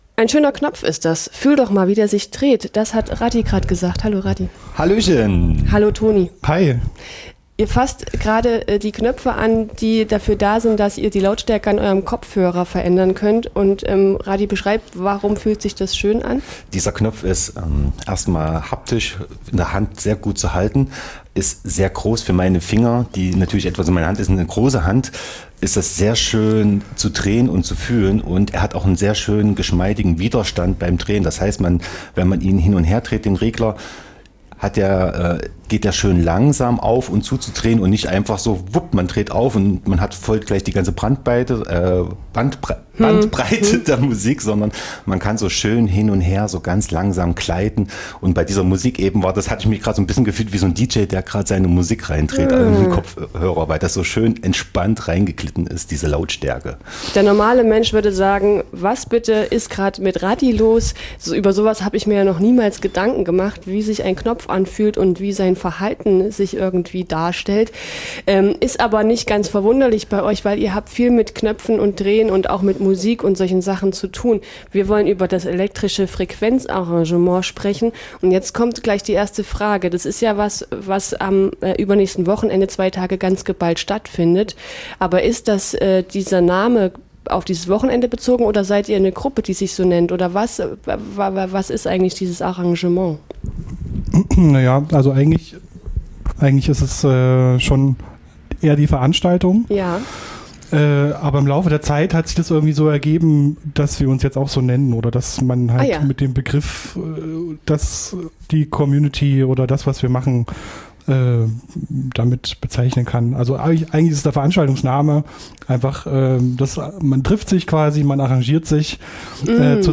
Elektrisches Frequenz Arrangement 2025 | Interview